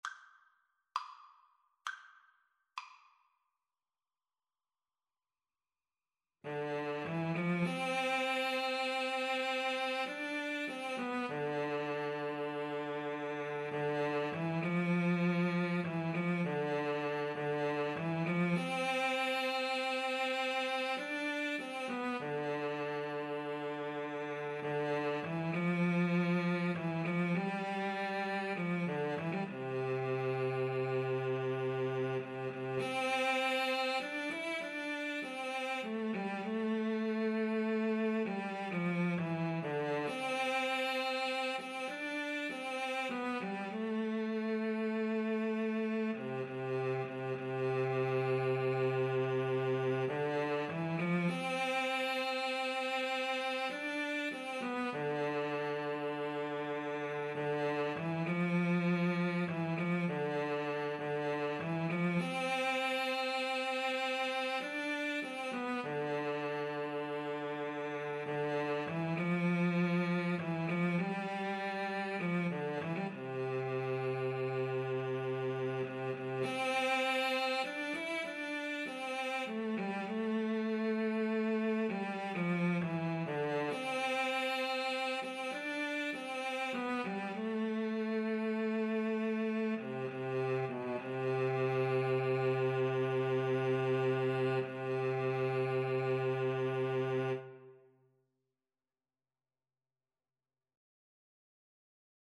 6/8 (View more 6/8 Music)
Cello Duet  (View more Easy Cello Duet Music)